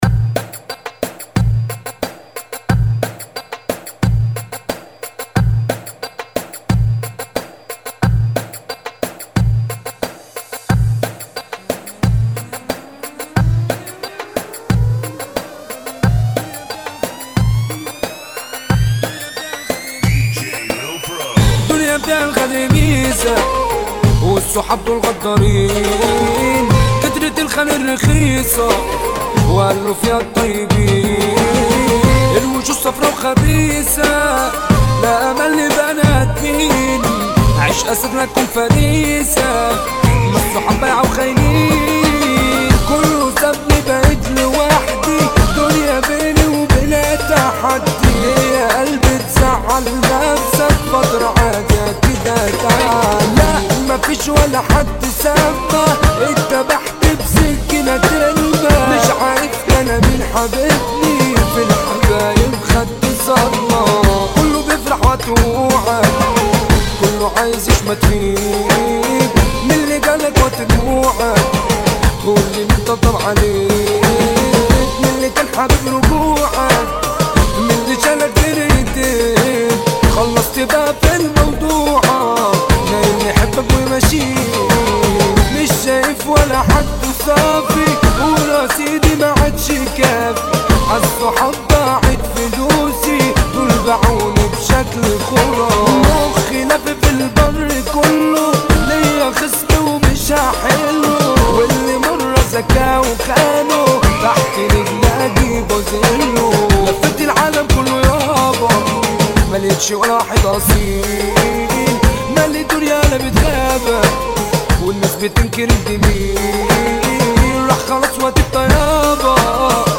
[ 90 Bpm ] - مهرجان